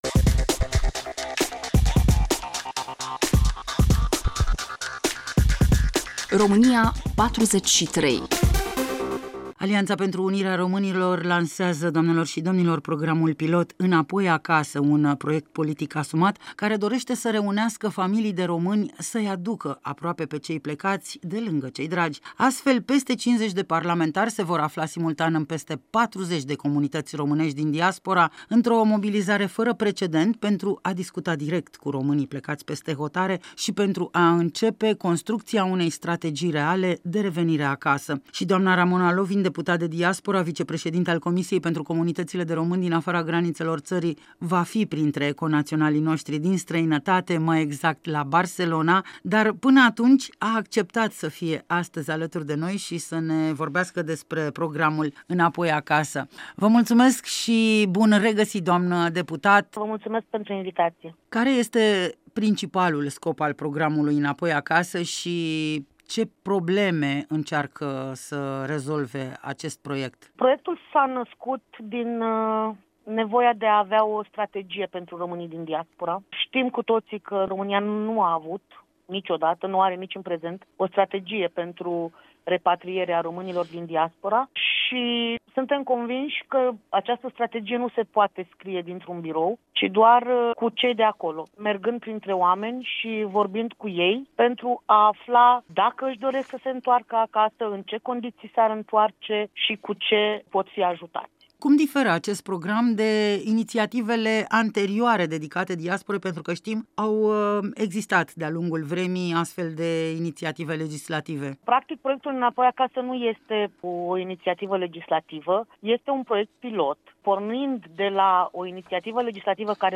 „Înapoi Acasă”, un program lansat de Alianța pentru Unirea Românilor de Ziua Unirii Principatelor Române, aduce soluții concrete pentru revenirea românilor din Diaspora. Interviu cu Ramona Lovin, deputat de Diaspora, vicepreşedinte al Comisiei pentru comunităţile de români din afara graniţelor ţării.